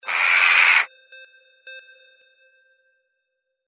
Crow Jackdaw 12A
Stereo sound effect - Wav.16 bit/44.1 KHz and Mp3 128 Kbps
Individual Jackdaw aggressive 'Caw'.